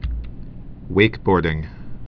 (wākbôrdĭng)